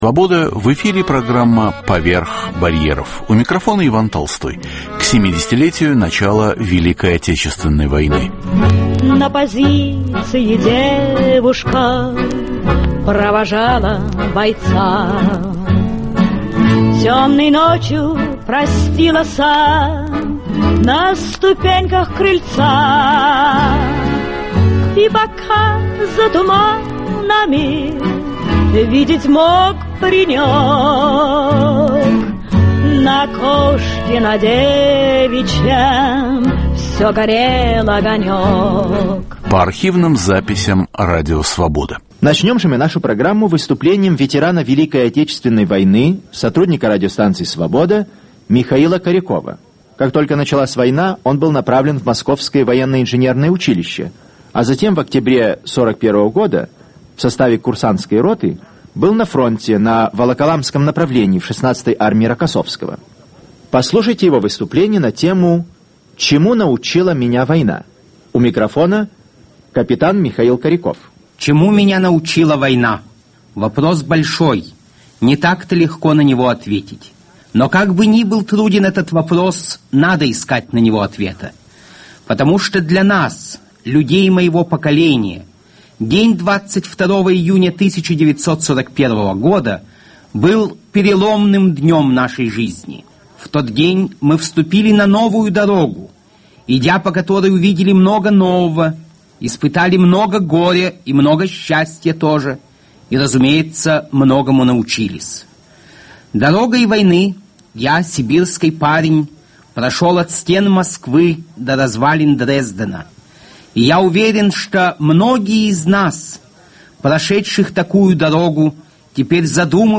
Каждый выпуск программы сопровождают новые музыкальные записи.